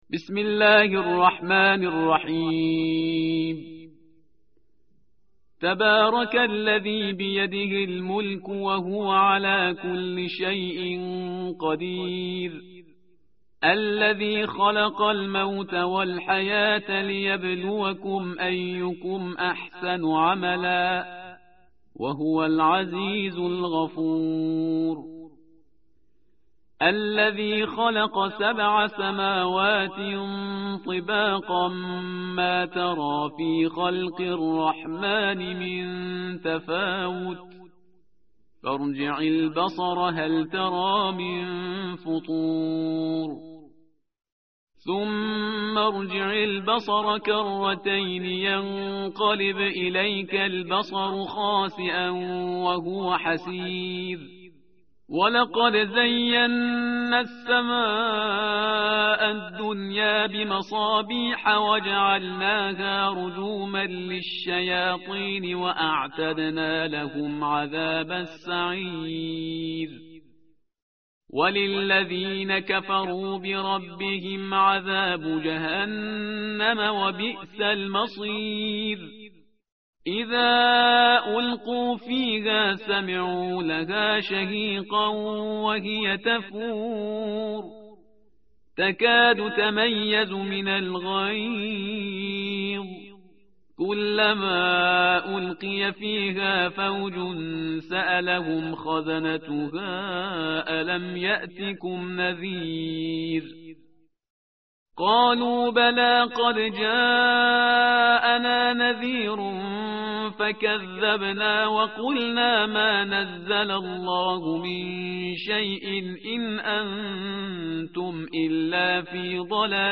tartil_parhizgar_page_562.mp3